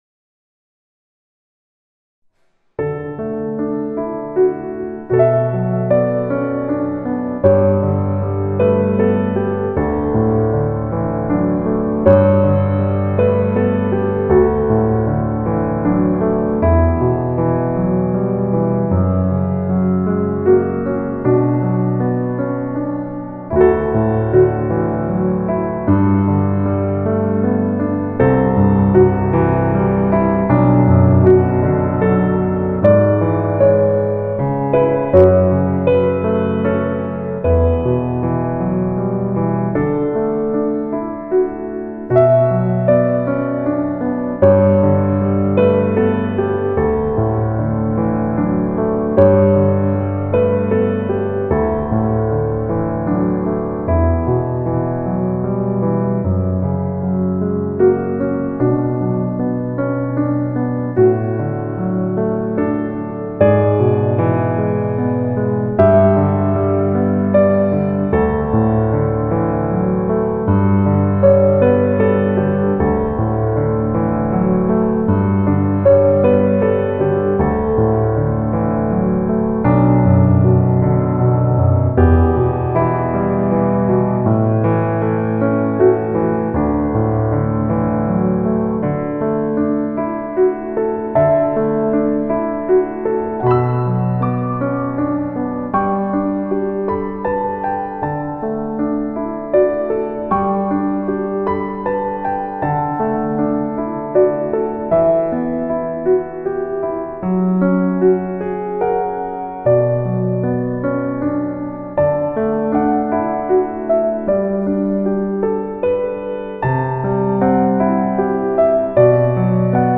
피아노 커버 piano cover